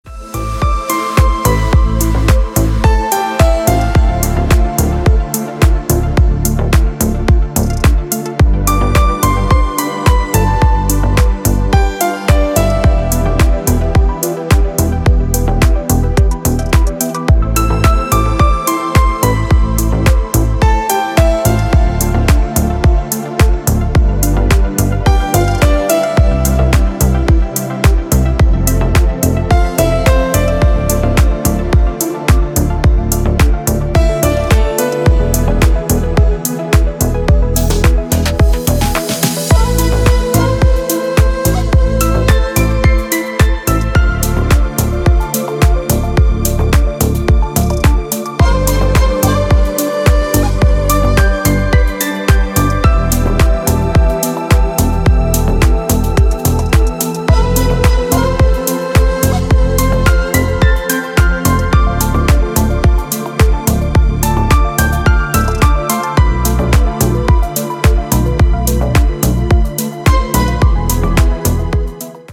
Мелодичная музыка на звонок